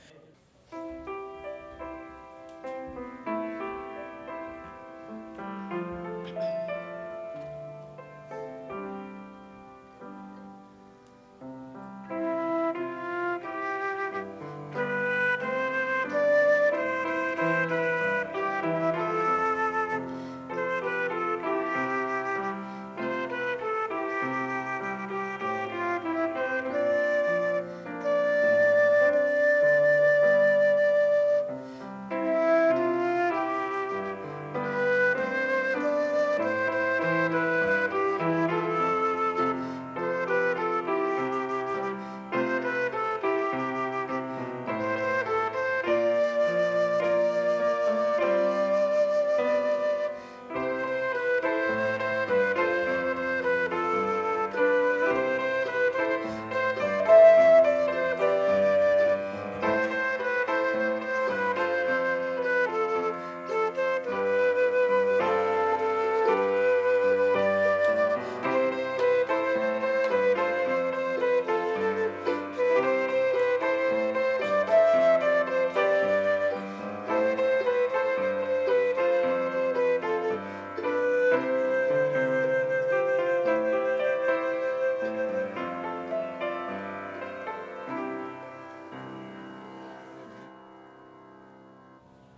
Shout-To-The-Lord-Flute.mp3